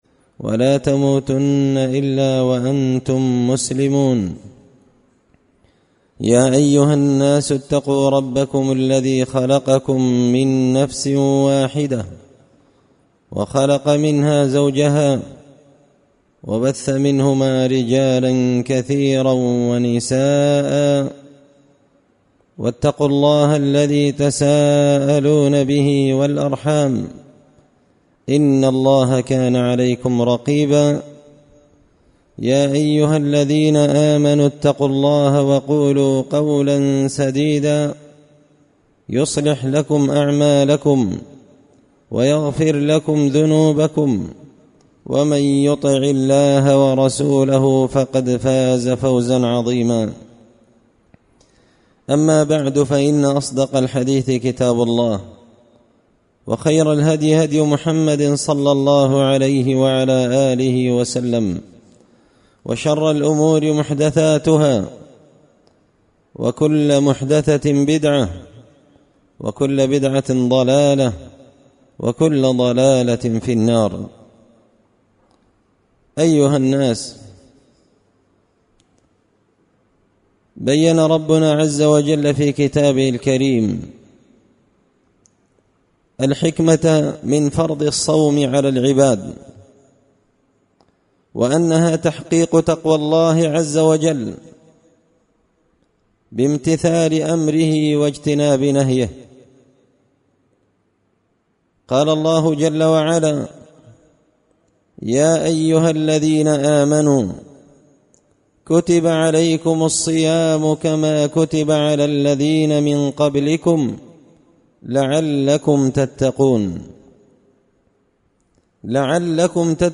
خطبة جمعة بعنوان – الحكمة من فرضية الصيام
دار الحديث بمسجد الفرقان ـ قشن ـ المهرة ـ اليمن